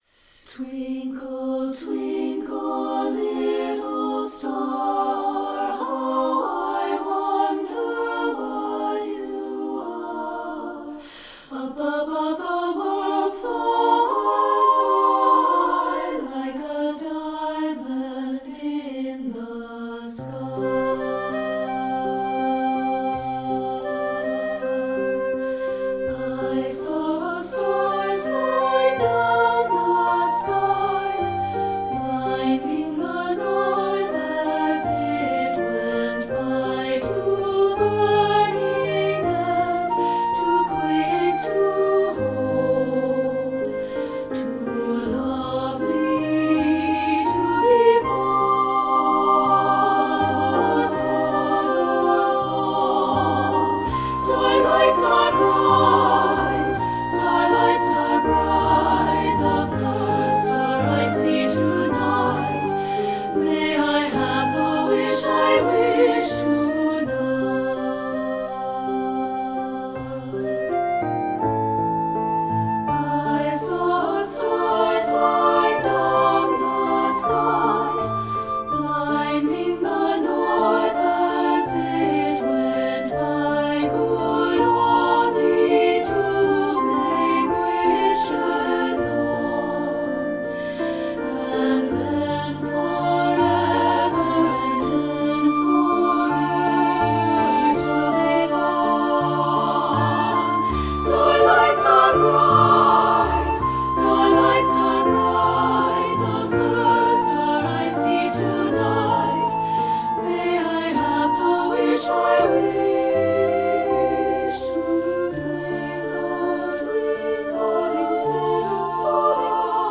Voicing: SSA, Piano and Flute